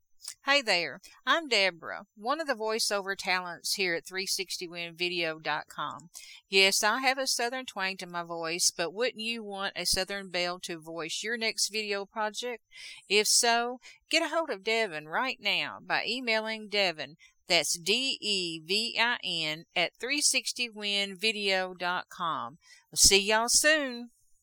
Voice Talent
Creative, energetic, adaptable to various styles and characters.